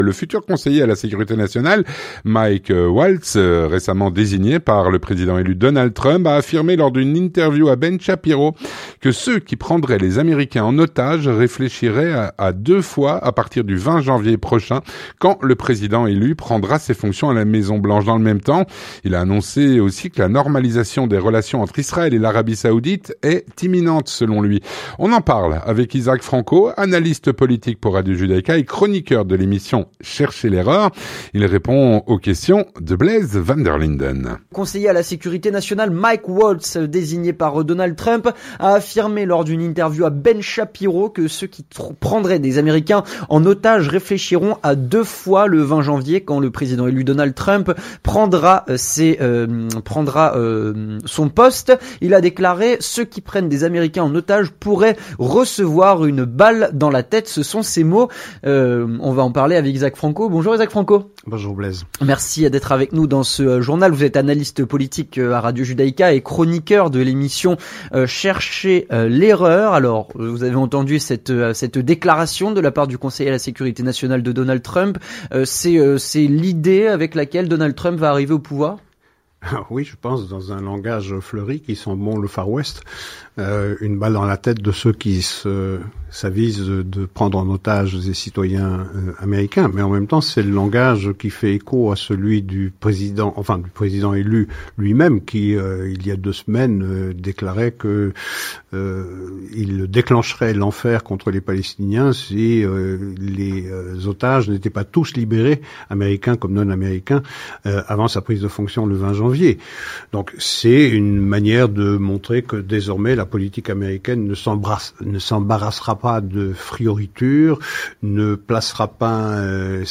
L'entretien du 18H - "Ceux qui voudraient prendre des Américains en otages y réfléchiront à deux fois à l'avenir".